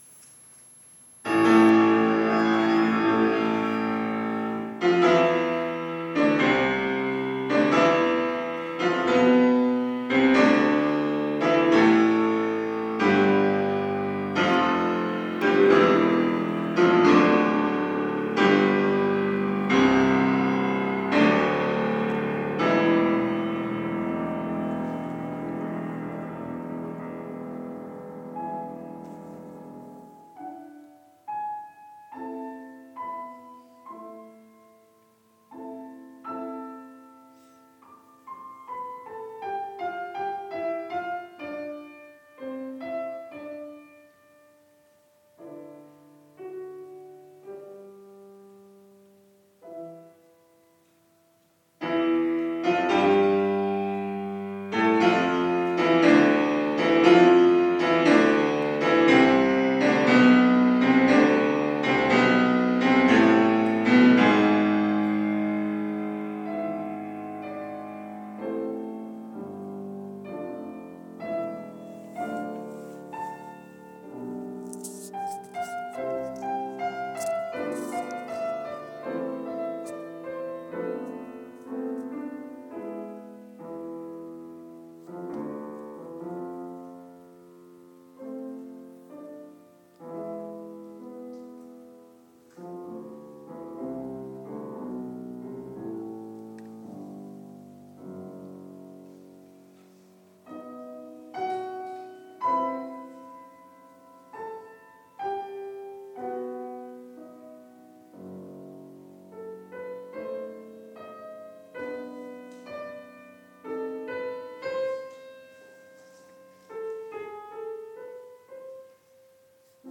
klavierduett 4